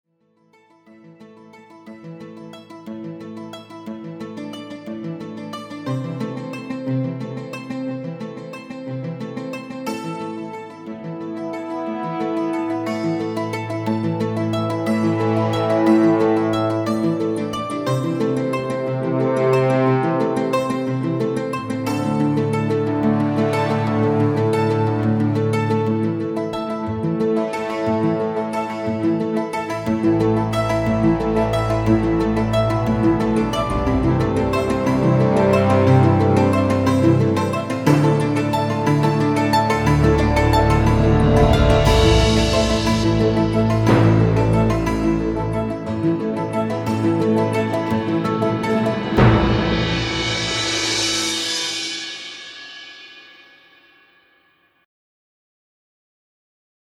one of Britain’s leading open air drama productions